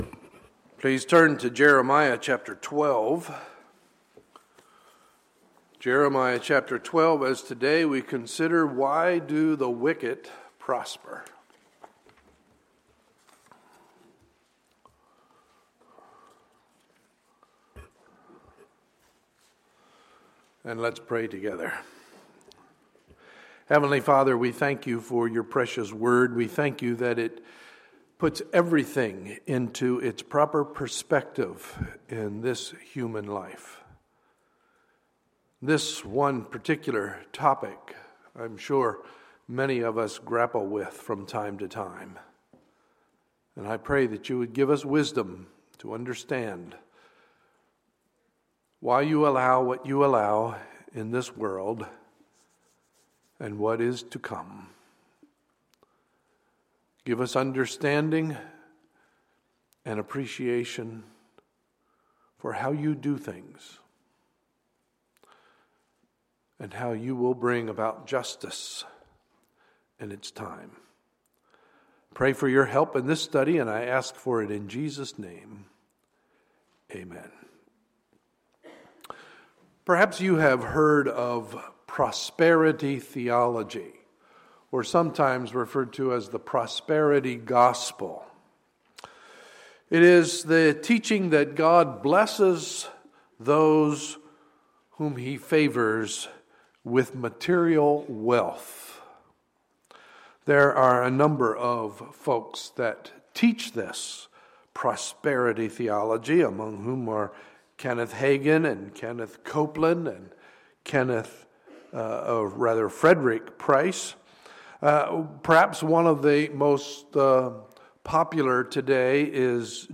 Sunday, April 12, 2015 – Sunday Morning Service